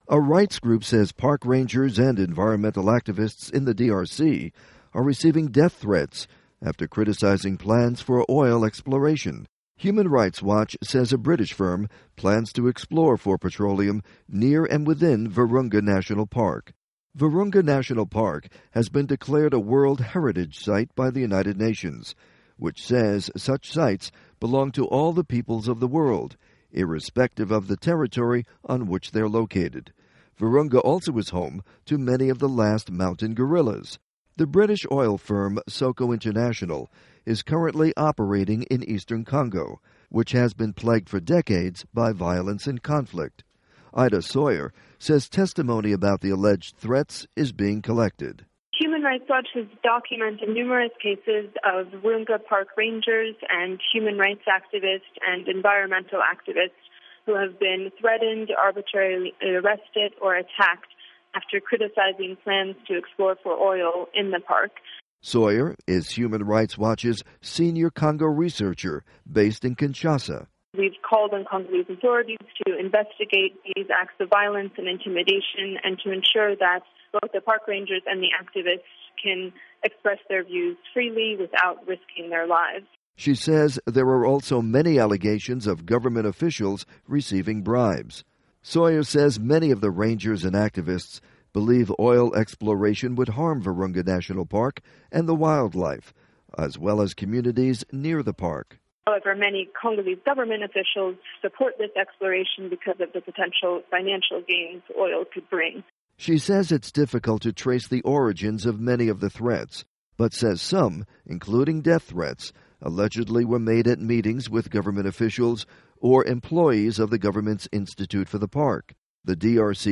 report on alleged threats in the DRC